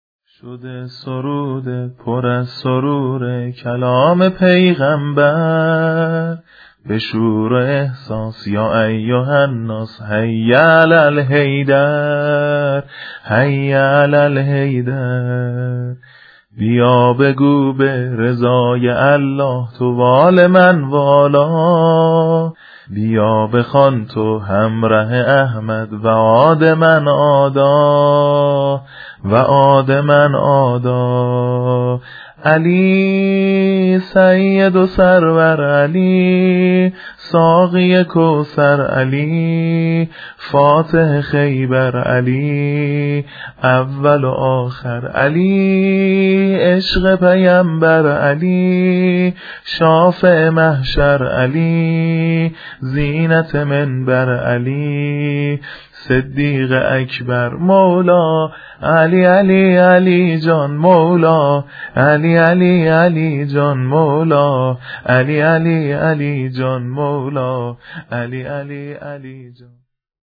عید غدیر
شور ، سرود